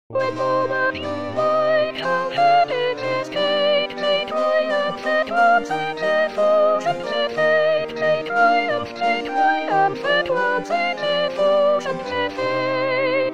Soprano
05-when-monarchs_Soprano.mp3